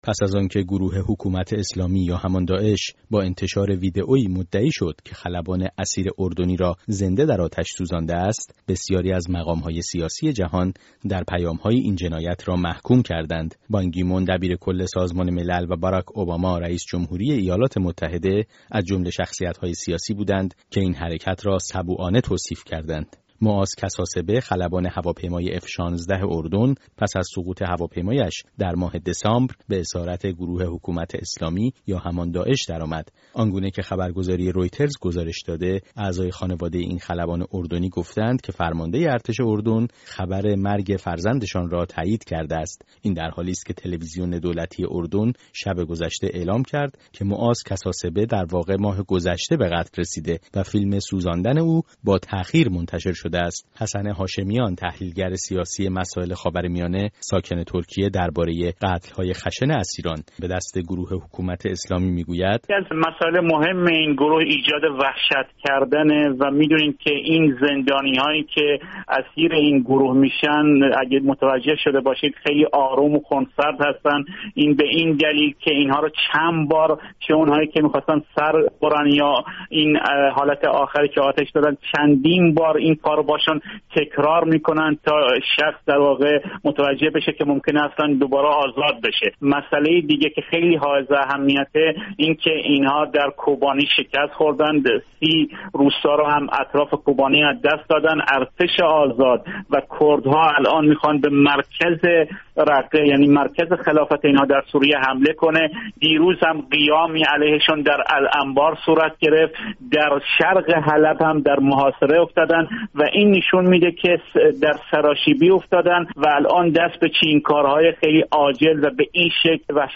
گفت‌وگوی
تحلیلگر سیاسی مسائل خاورمیانه، در مورد قتل خلبان اردنی